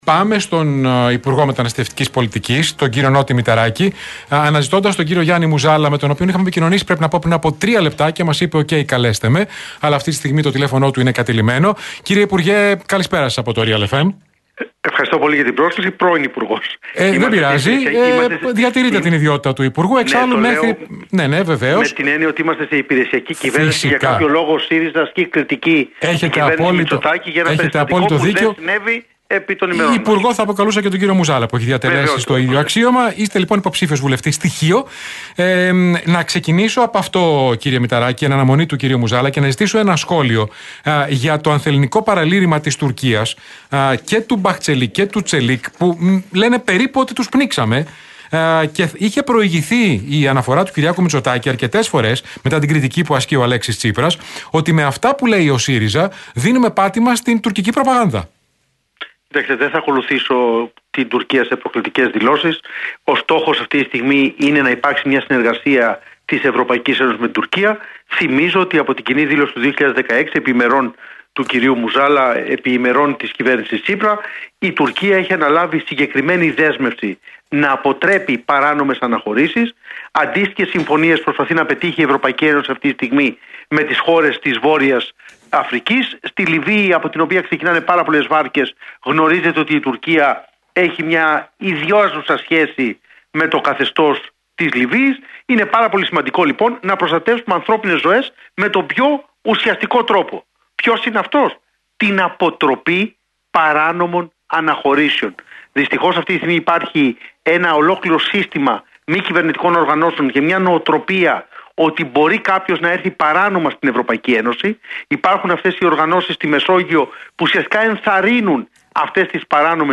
στο κεντρικό μαγκαζίνο του Realfm 97,8.